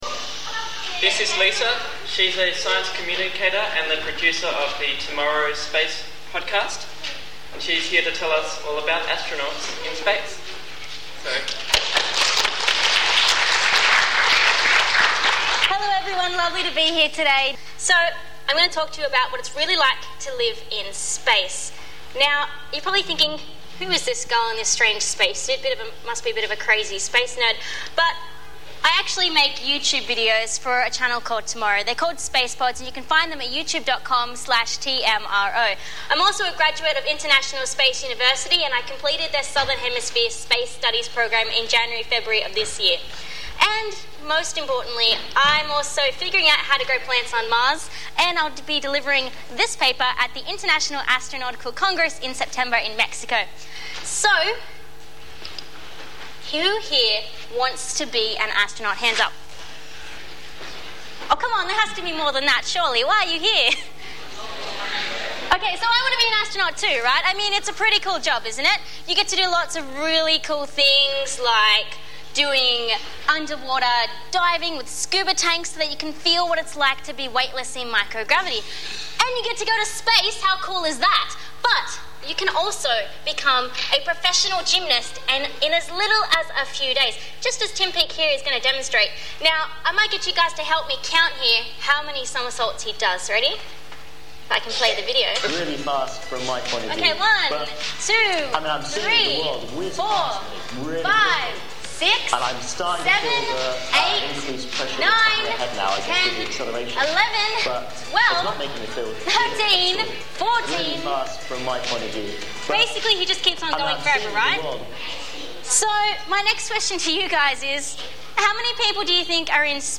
Space Show 2018 Lectures
(Recorded at Melbourne Final Frontier Festival)